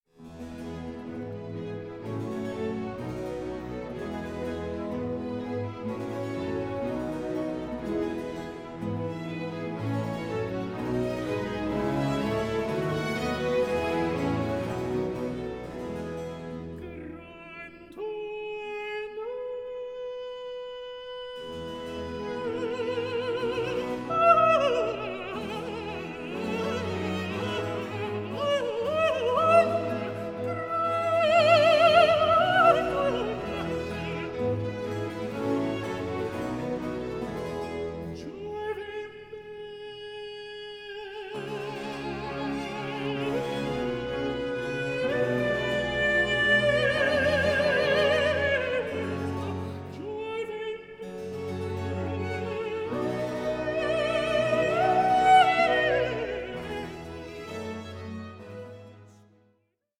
period-instrument group